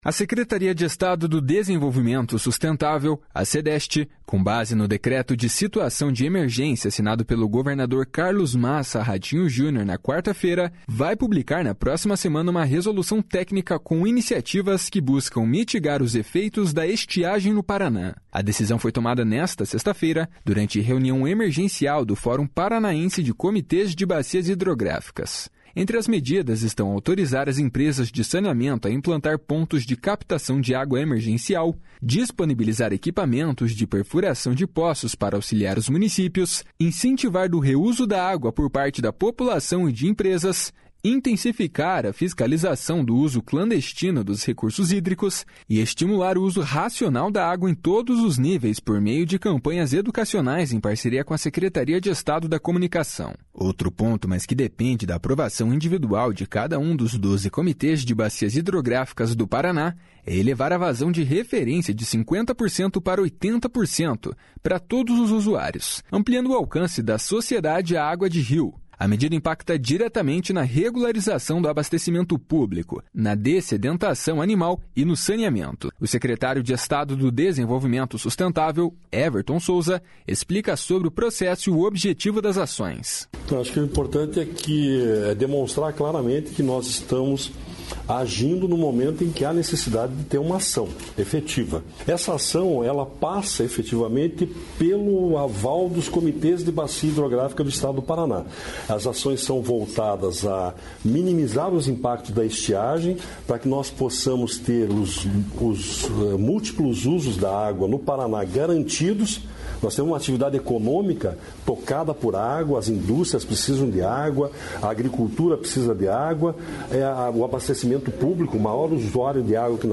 O secretário de Estado do Desenvolvimento Sustentável, Everton Souza, explica sobre o processo e o objetivo das ações. // SONORA EVERTON SOUZA //